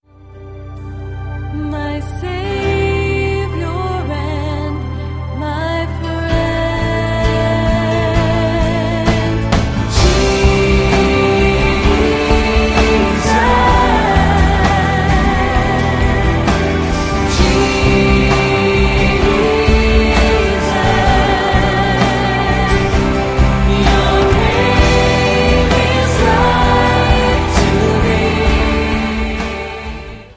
live worship
• Sachgebiet: Praise & Worship